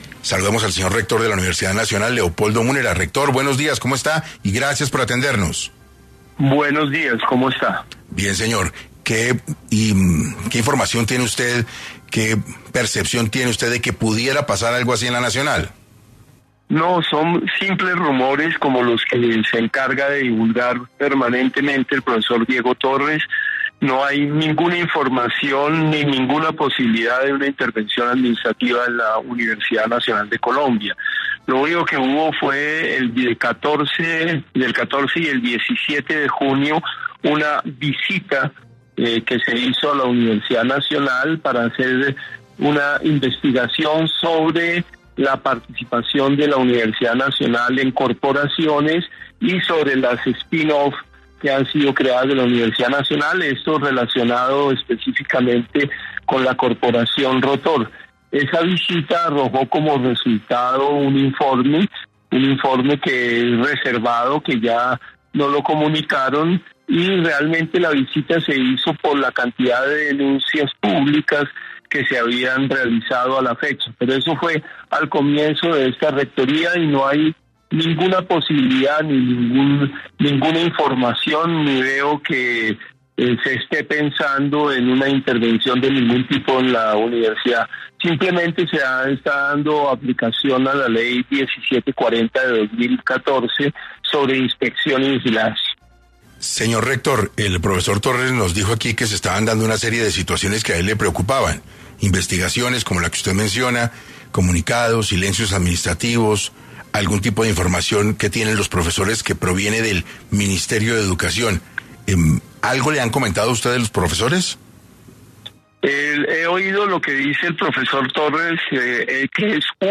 Hoy en entrevista para 6AM,el rector de la Universidad Nacional, Leopoldo Múnera, aclaró la situación administrativa que vive la universidad.